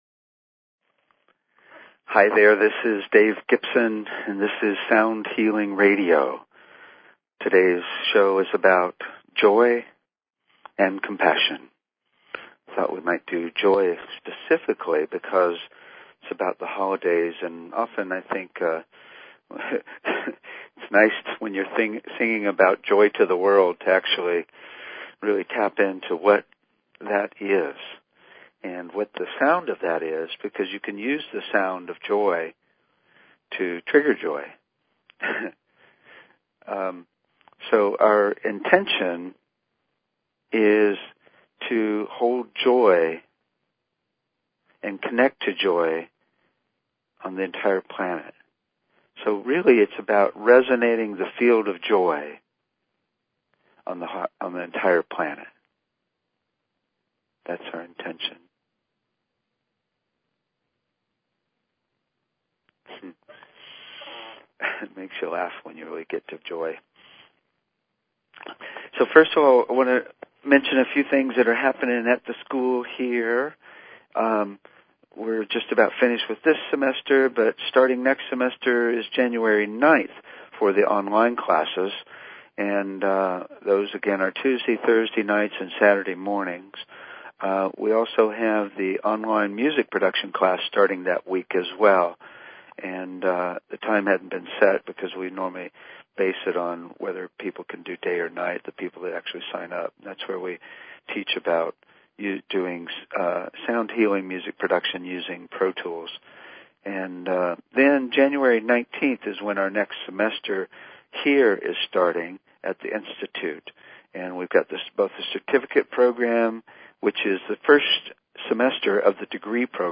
Talk Show Episode, Audio Podcast, Sound_Healing and Courtesy of BBS Radio on , show guests , about , categorized as
THE SOUND OF JOY AND COMPASSIONFor the holidays we look at and experience sounds of joy and compassion for those who aren't joyful.